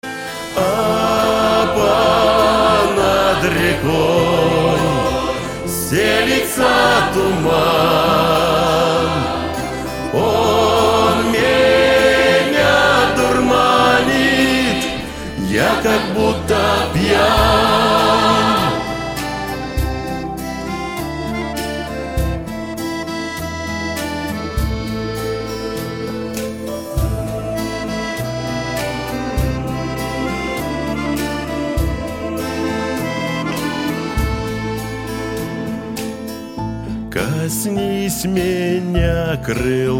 фолк